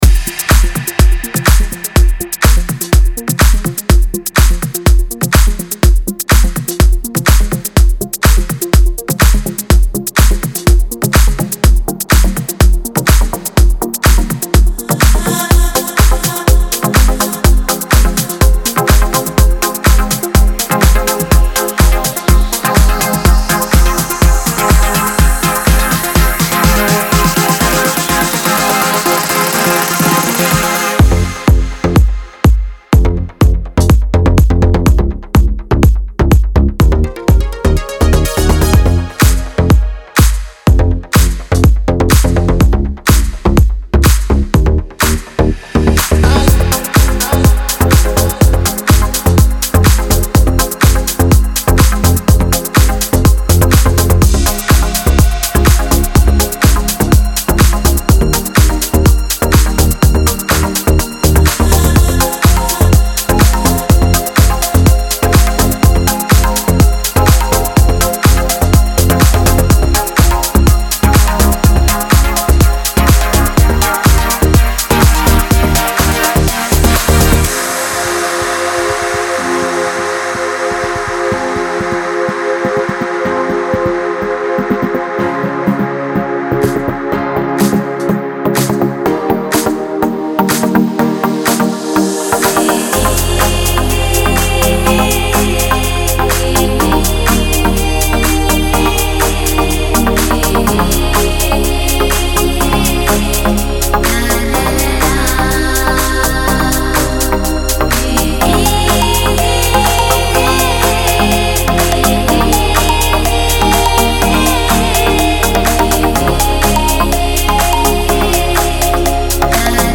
это завораживающая композиция в жанре электронной музыки